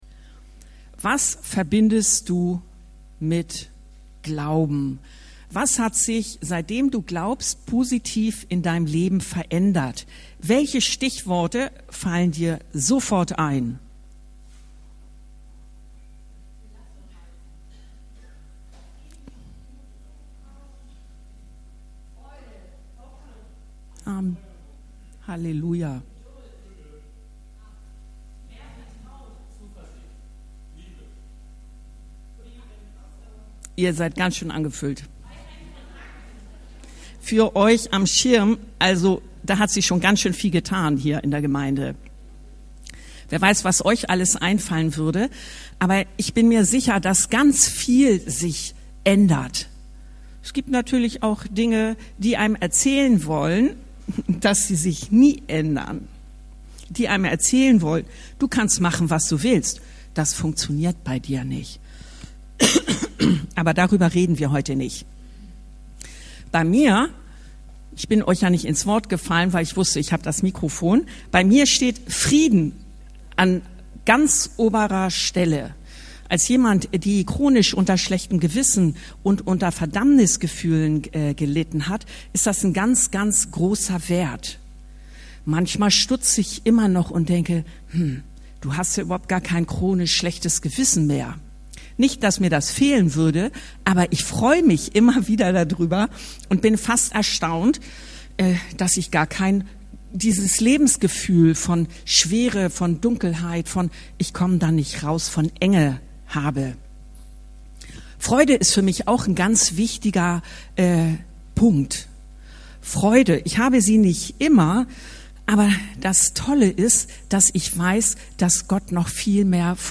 Der Gott der Hoffnung, Röm 15,13 ~ Anskar-Kirche Hamburg- Predigten Podcast